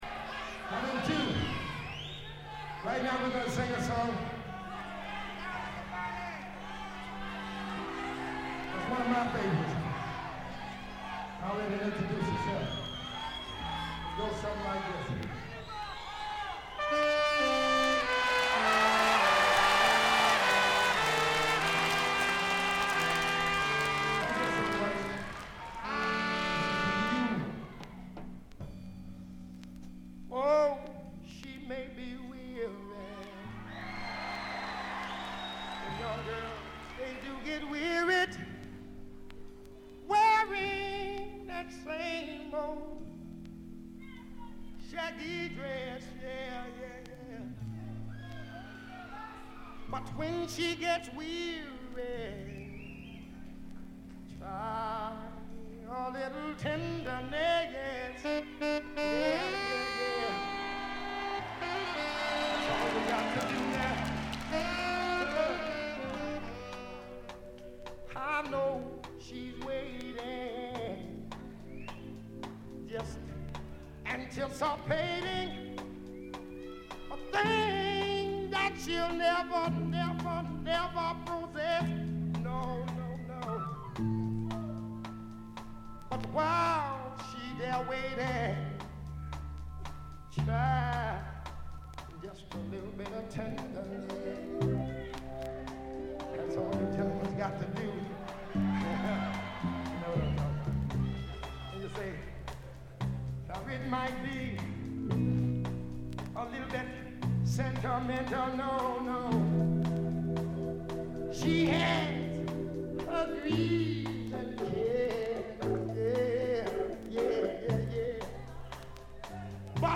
わずかなノイズ感のみ。
魂のライヴ・パフォーマンスが収められた真の名盤。
試聴曲は現品からの取り込み音源です。
Recorded at the Olympia Theatre, Paris; March 21, 1967.